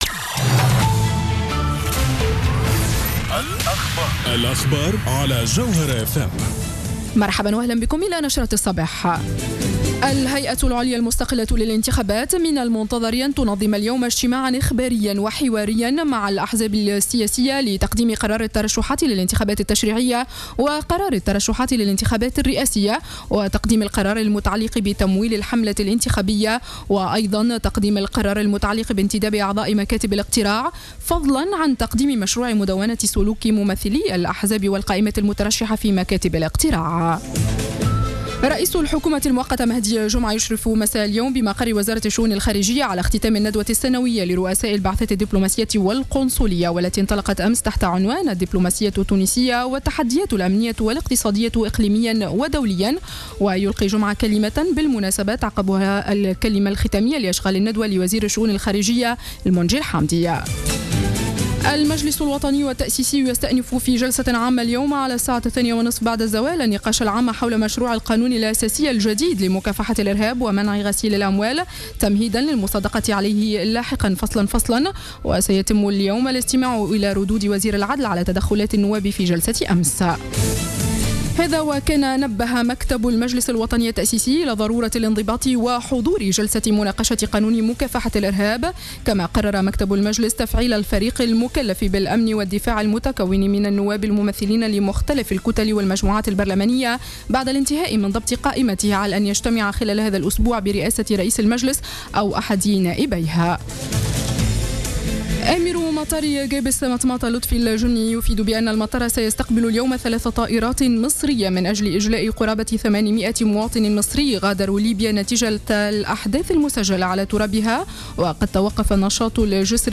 نشرة أخبار السابعة صباحا ليوم الثلاثاء 12-08-14